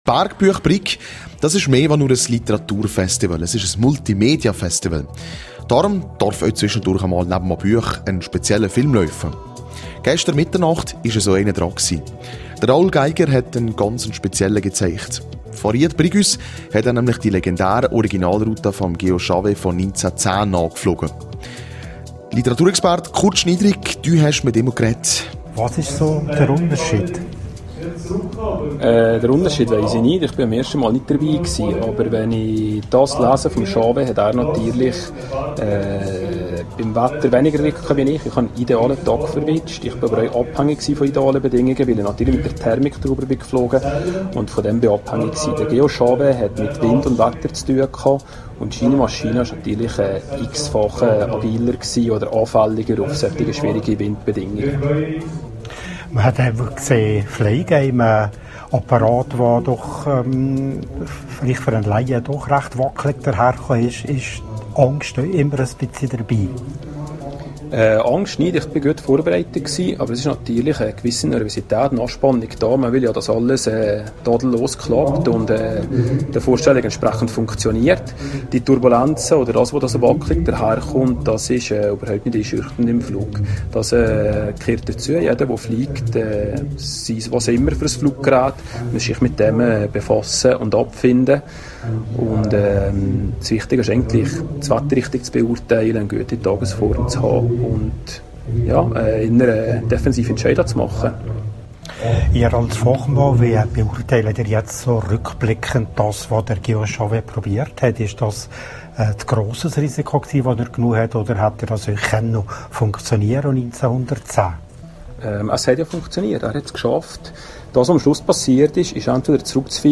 In meinem Interview